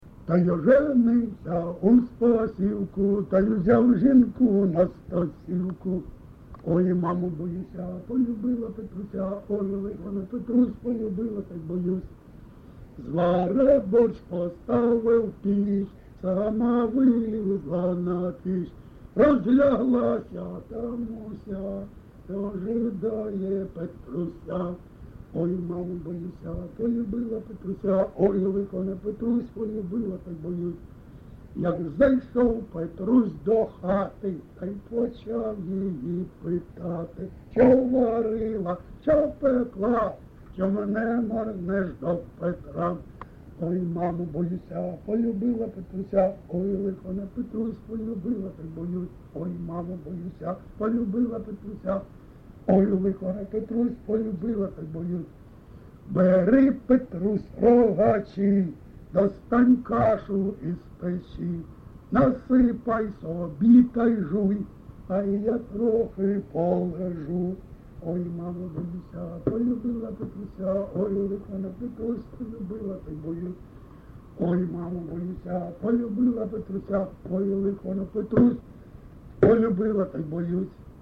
ЖанрЖартівливі
Місце записус-ще Красноріченське, Кремінський район, Луганська обл., Україна, Слобожанщина